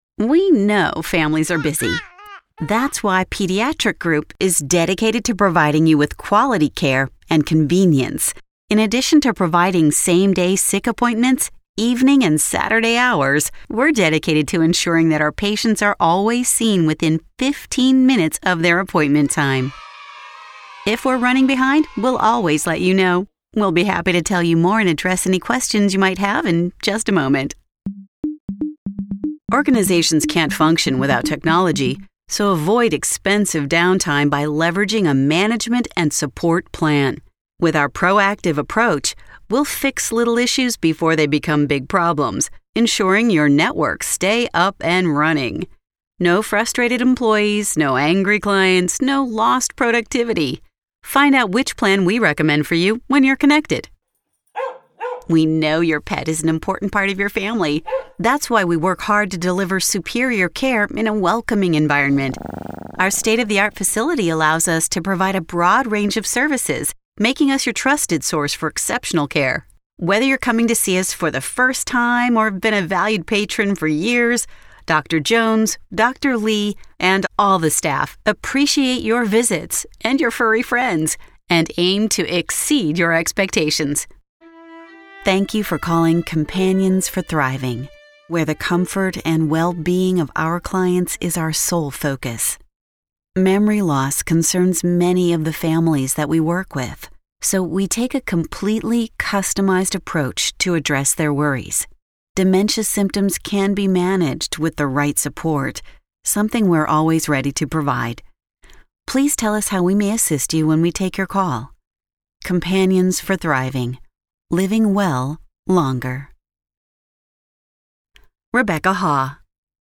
Female Voice Over, Dan Wachs Talent Agency.
Natural, Conversational, Best Friend, Employer.
IVR, Phones